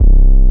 59 BASS 1 -L.wav